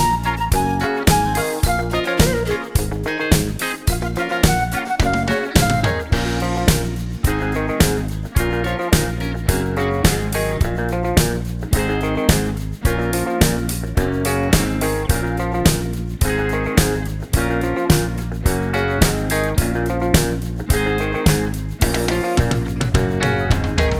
No Main Guitar Pop (1980s) 3:16 Buy £1.50